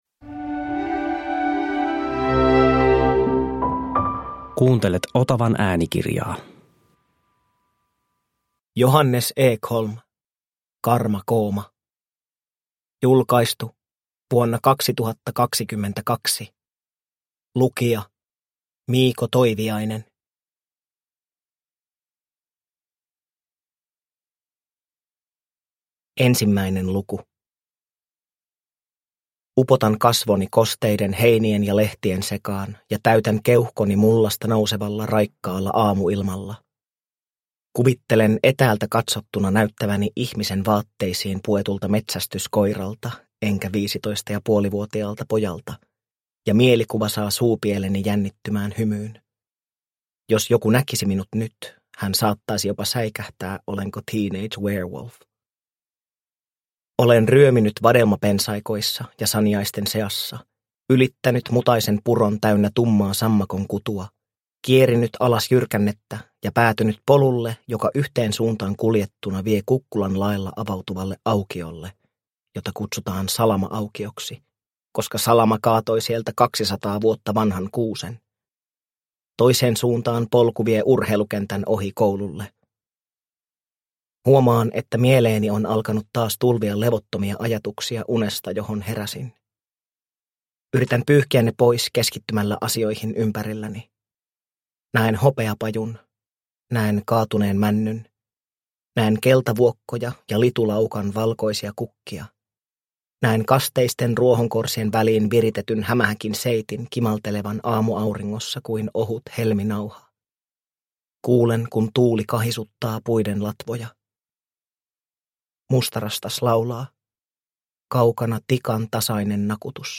Karmakooma – Ljudbok – Laddas ner